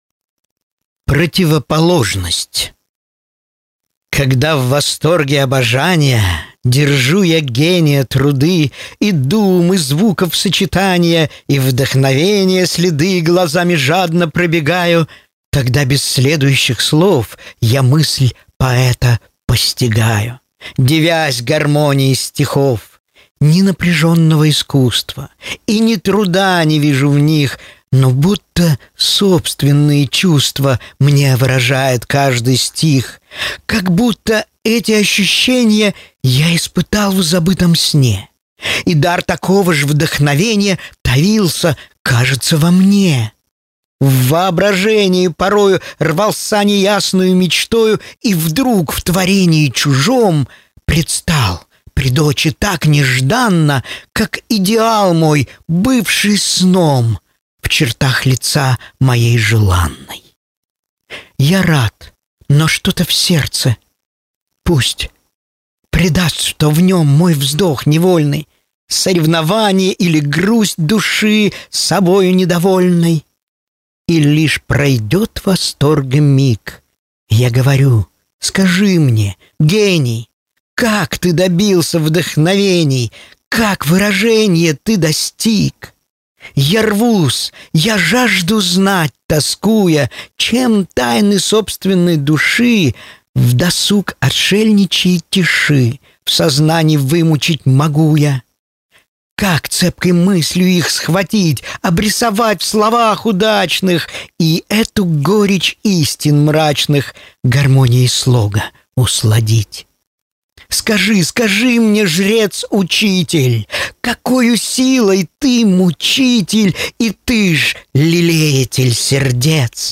Аудиокнига Стихотворения | Библиотека аудиокниг